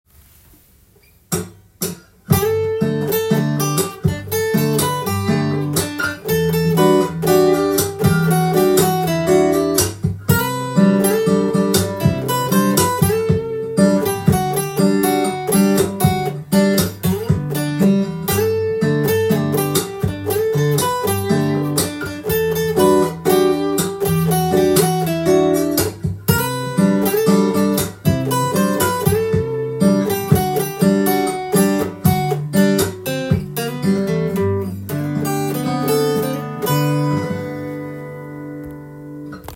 譜面通りに弾いてみました
アコースティックギターでサビのメロディーラインを弾けるように
ギターで弾きやすくするためにキーをGにしています。
メロディーはすべてGのメジャースケールで構成されているので
しいていえば、難しいのは、洋楽によくある裏のリズムを多用されている点です。
また伴奏のコードは、小節の頭にパームと言われる奏法を使ってみました。
親指付け根でボディーを叩きながらコードのベース音を弾いてあげると
add9などのテンションコードを入れていますので、おしゃれな響きで